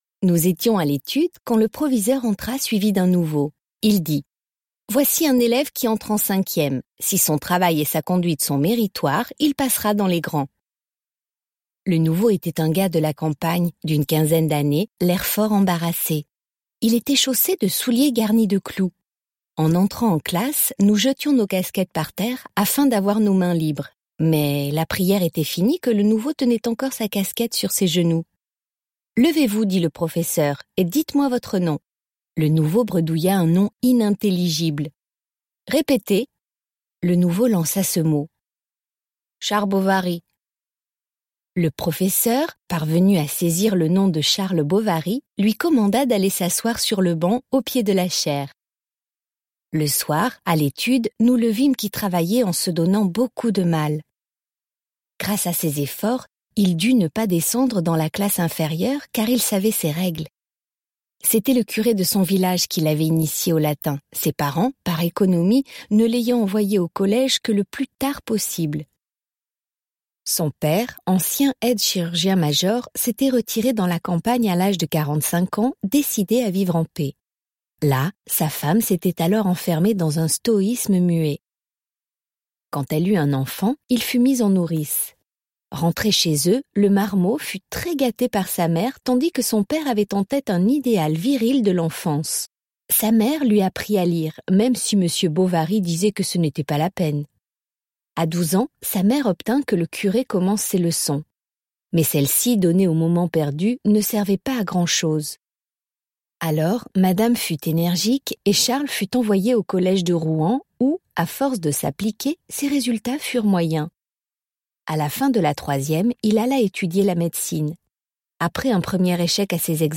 Madame Bovary (FR) audiokniha
Ukázka z knihy